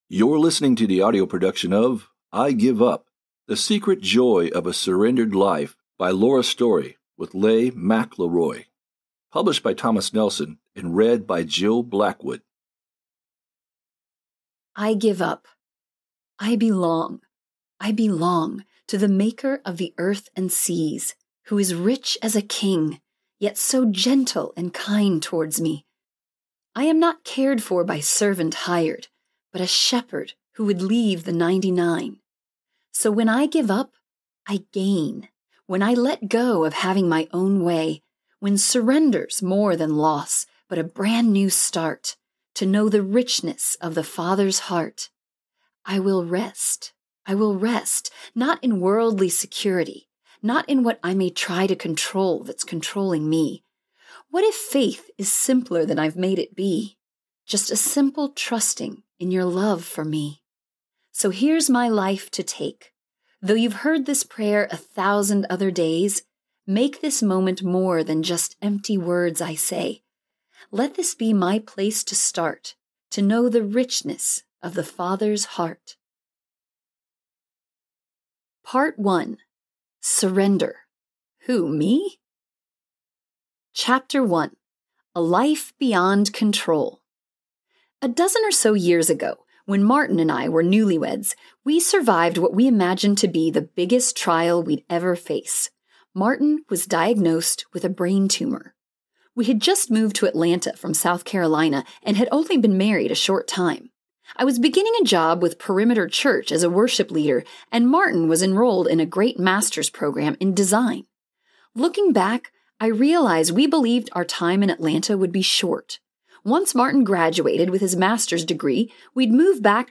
I Give Up Audiobook
Narrator
5.33 Hrs. – Unabridged